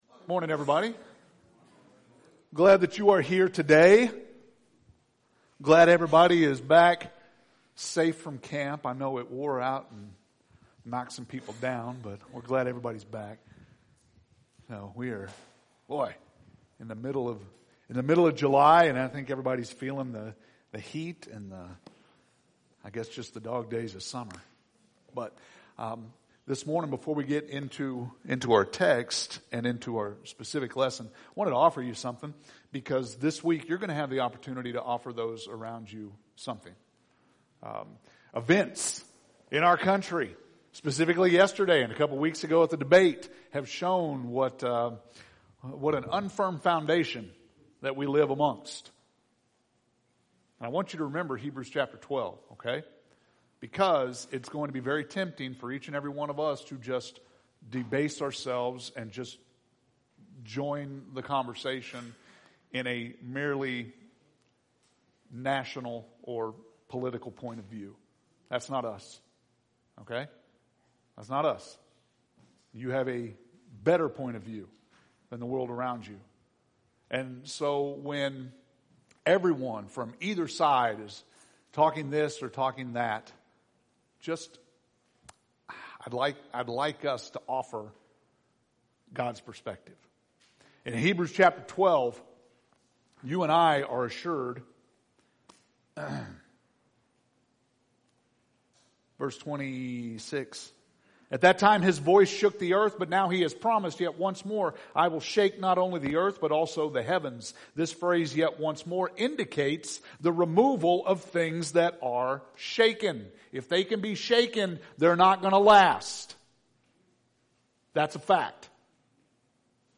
July 14th – Sermons